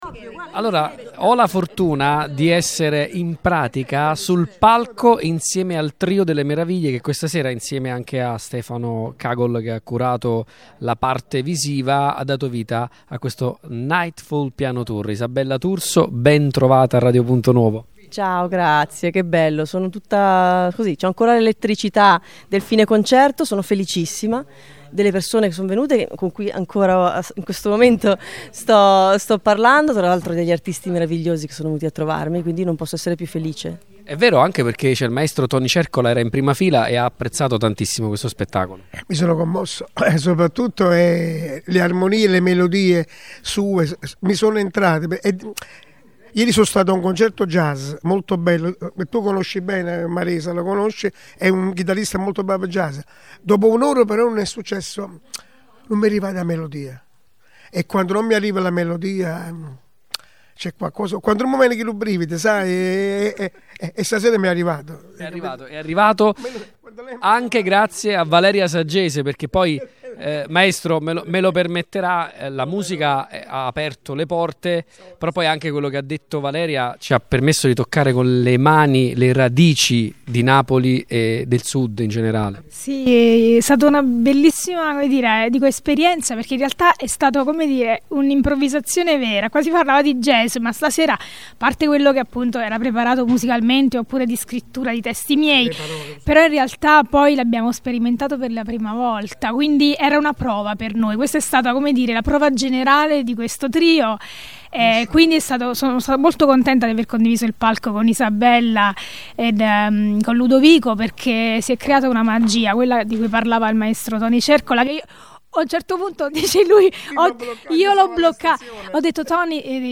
INTERVISTE-NIGHTFALL-PIANO-TOUR.mp3